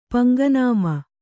♪ paŋga nāma